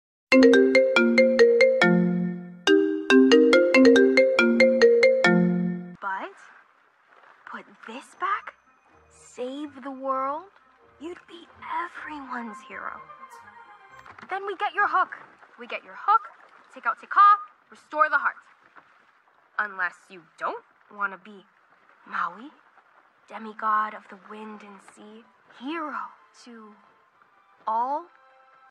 Disclaimer: This is a fun fake call, not affiliated with any official character or brand.